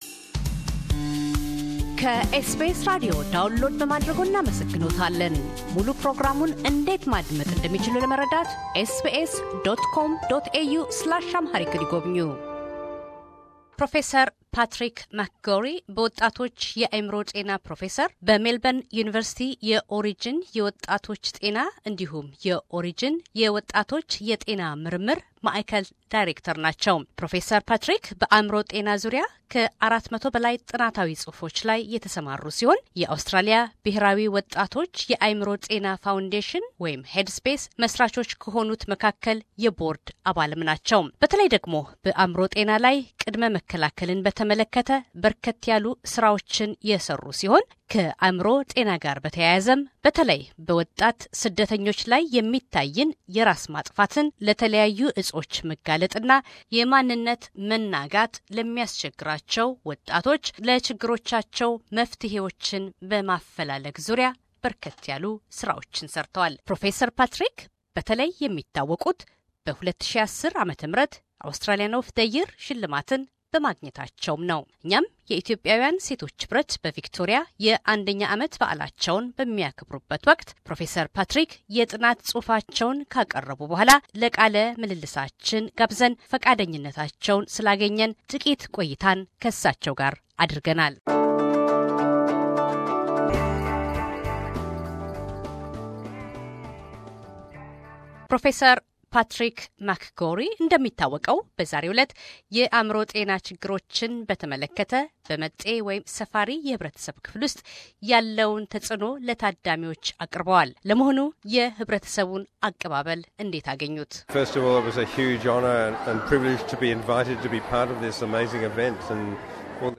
Interview with Prof Patrick McGorry AO